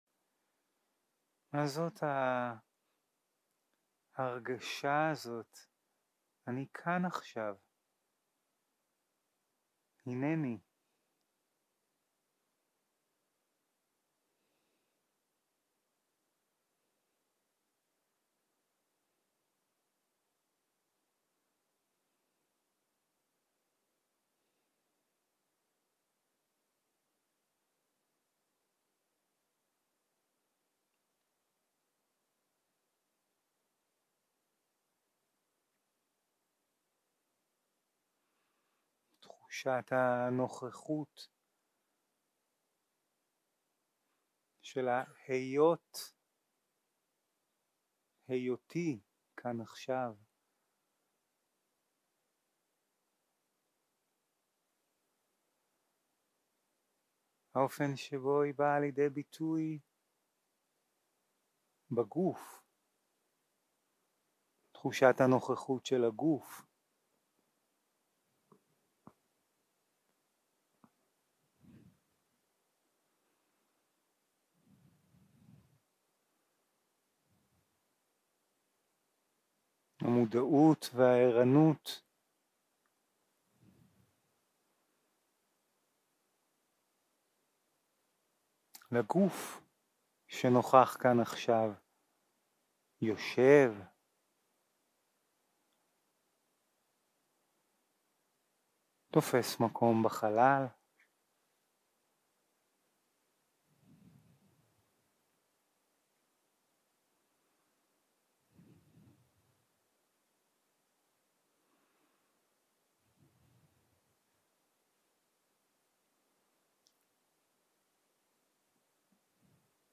יום 1 - הקלטה 1 - ערב - מדיטציה מונחית
Dharma type: Guided meditation